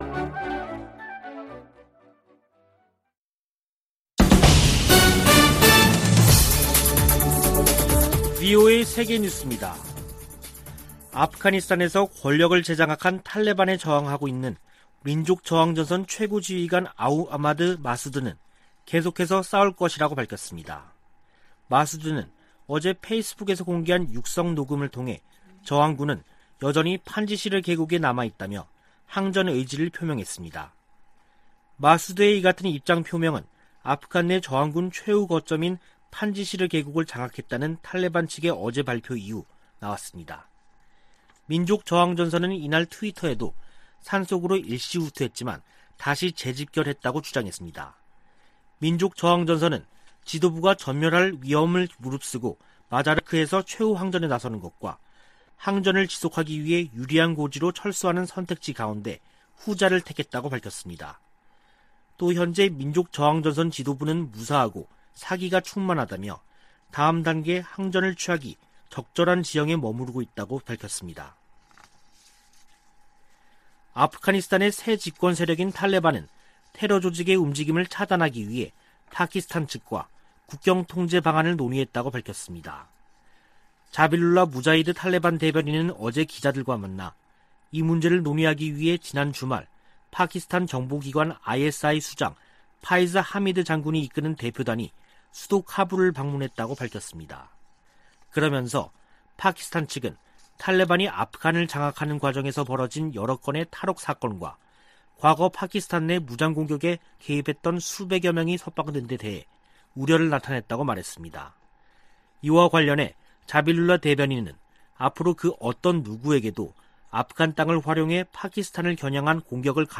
VOA 한국어 간판 뉴스 프로그램 '뉴스 투데이', 2021년 9월 7일 3부 방송입니다. 북한이 핵무기와 미사일 관련 국제 규칙을 노골적으로 무시하고 있다고 북대서양조약기구(NATOㆍ나토) 사무총장이 밝혔습니다. 오는 14일 개막하는 제 76차 유엔총회에서도 북한 핵 문제가 주요 안건으로 다뤄질 전망입니다. 아프가니스탄을 장악한 탈레반이 미군 무기를 북한에 판매하지 않을 것이라고 밝혔습니다.